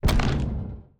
Bone Golem Armor Hit 2.wav